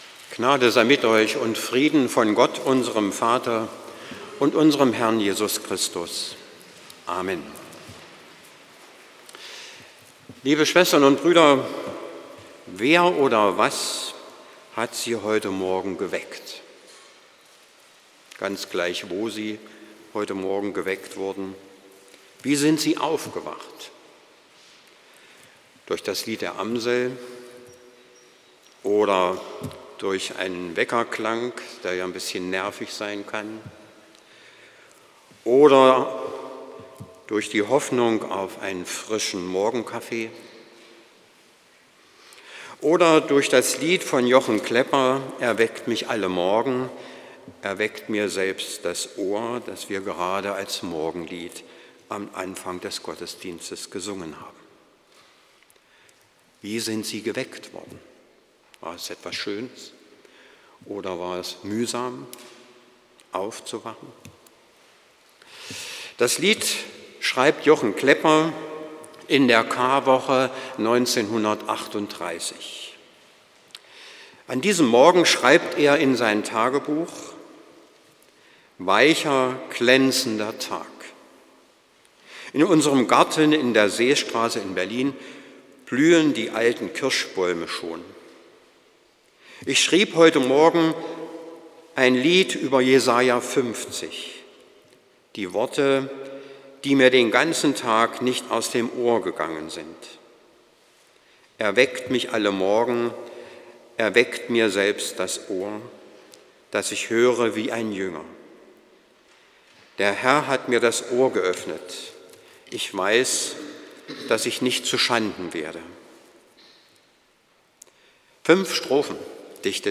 Predigt am Palmsonntag von Sup.